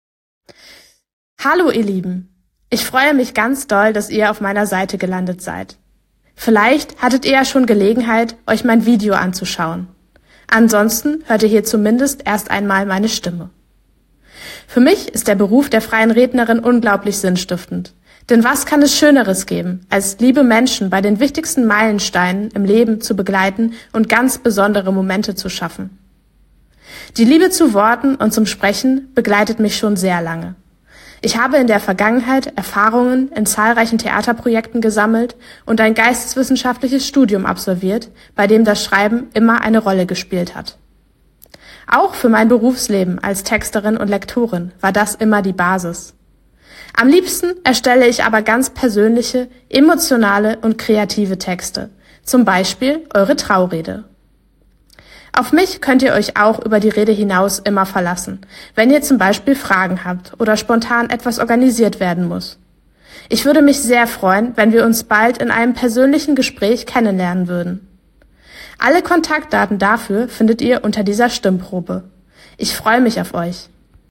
Stimmprobe1.mp3